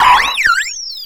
Cri de Shaymin dans sa forme Céleste dans Pokémon X et Y.
Cri_0492_Céleste_XY.ogg